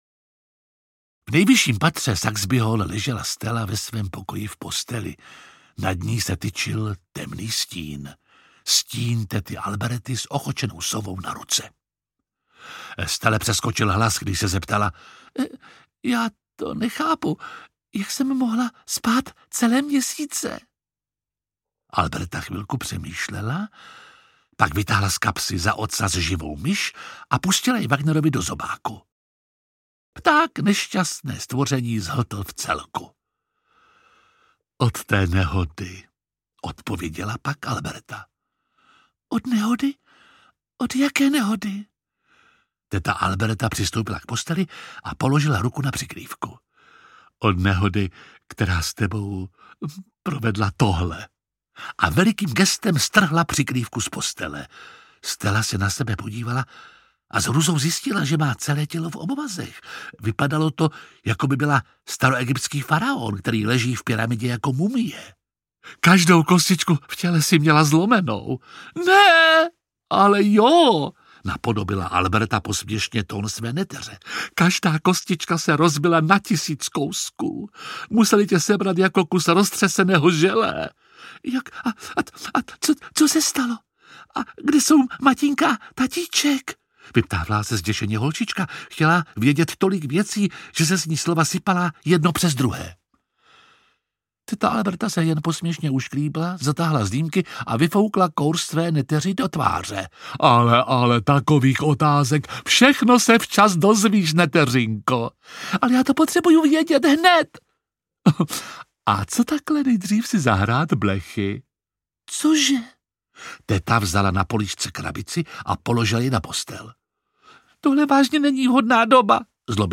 Příšerná teta audiokniha
Ukázka z knihy
Čte Jiří Lábus.
Vyrobilo studio Soundguru.
• InterpretJiří Lábus